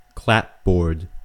Ääntäminen
UK : IPA : /ˈklæpˌbɔː(ɹ)d/ US : IPA : /ˈklæbɹd/